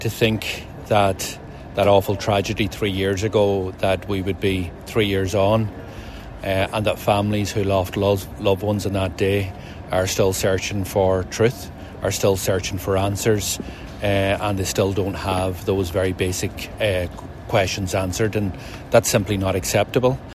Donegal TD Pearse Doherty, says the families deserve answers………………….